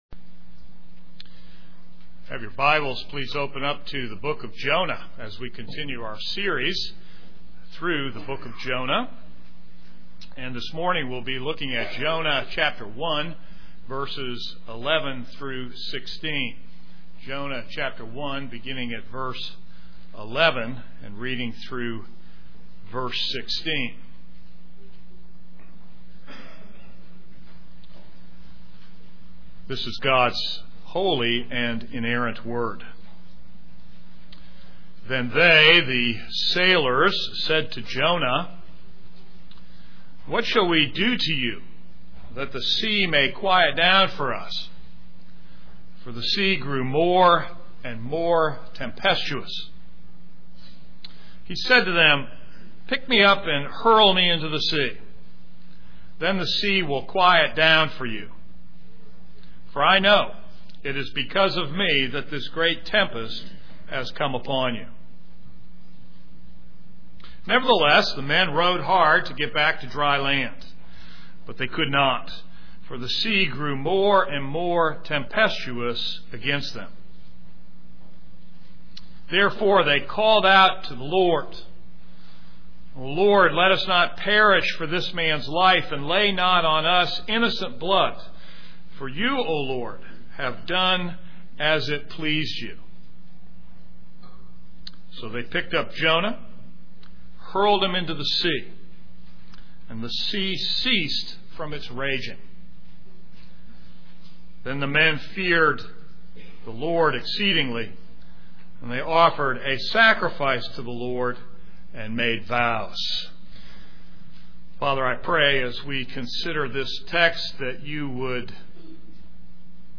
This is a sermon on Jonah 1:11-16.